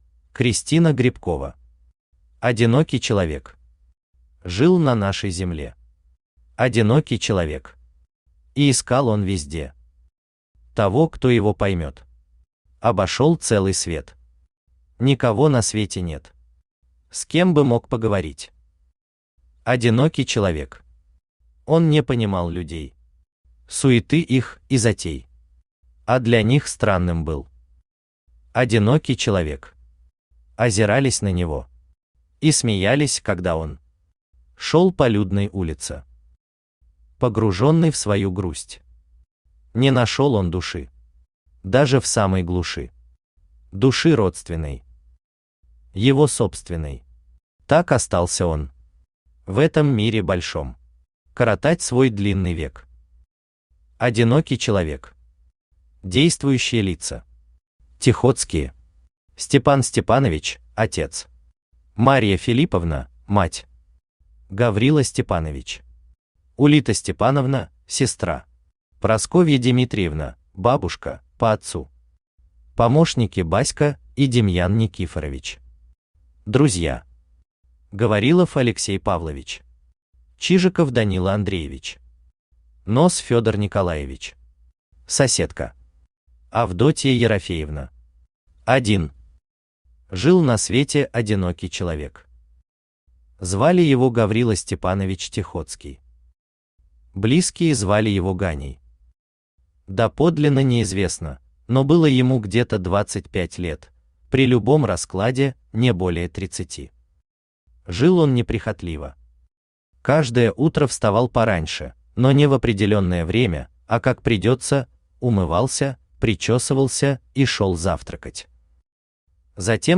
Аудиокнига Одинокий Человек | Библиотека аудиокниг